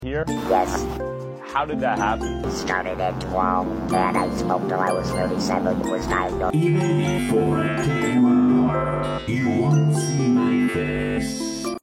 fnaf 2 Bonnie ahh voice sound effects free download